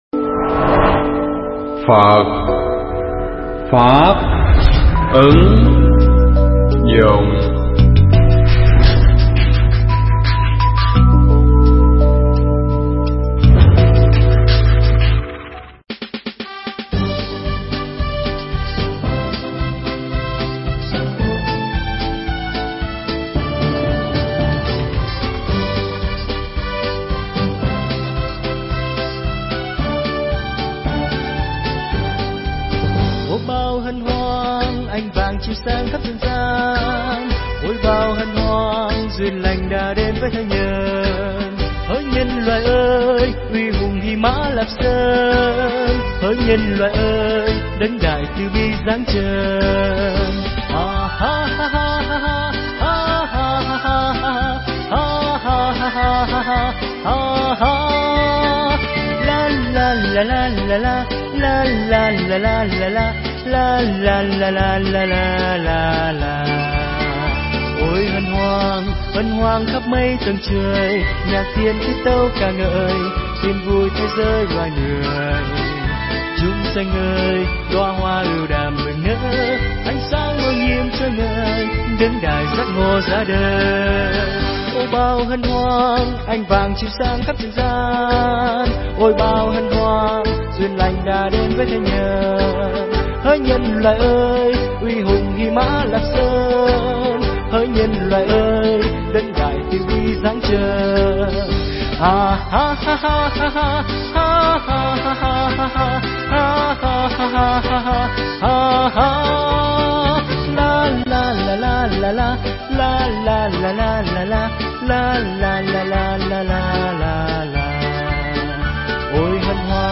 Nghe Mp3 thuyết pháp Tìm Hiểu Sự Hiện Diện Của Phật Đản Sanh